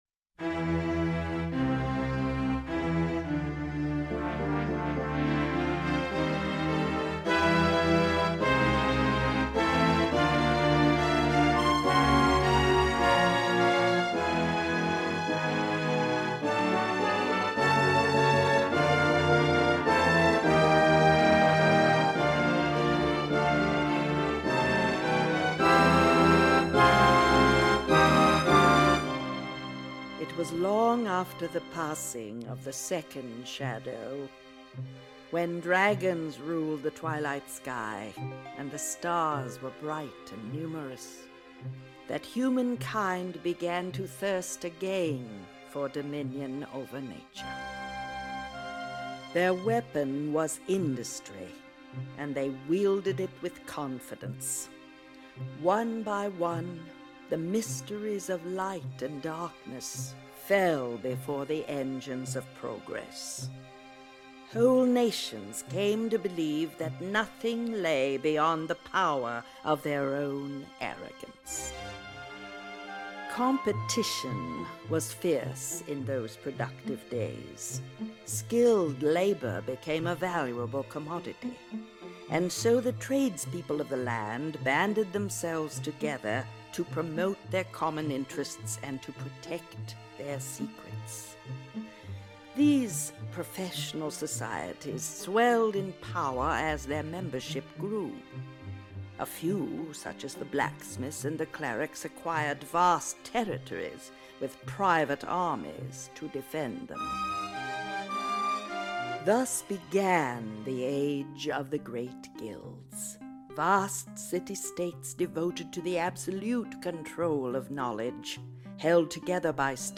You have to listen to the indispensable audio drama , that explains the nature and history of the Loom world, as the game is a direct continuation of said story.
loom-audio-drama.mp3